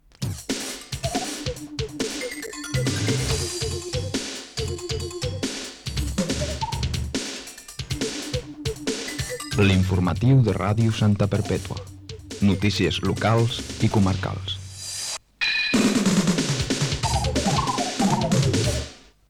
Indicatiu del programa
FM